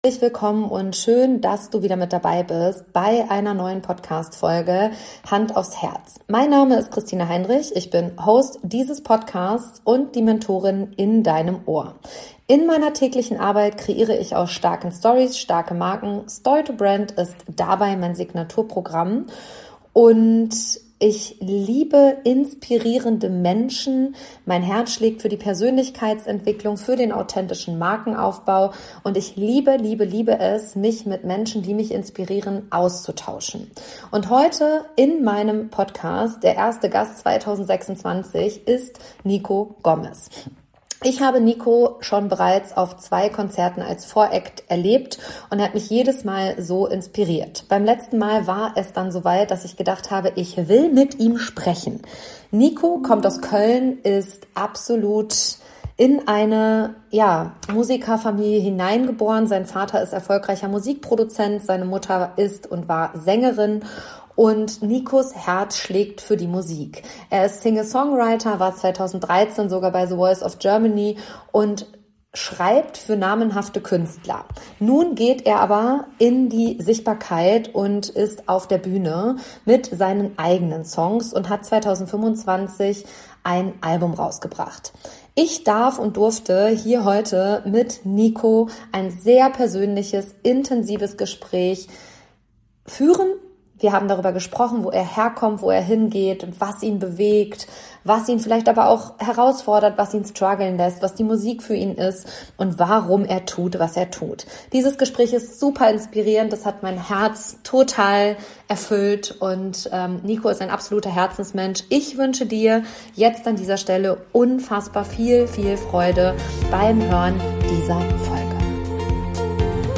Ein Gespräch über Authentizität, über das Spannungsfeld zwischen Kunst und Business – und über das große „Warum“ hinter der Musik.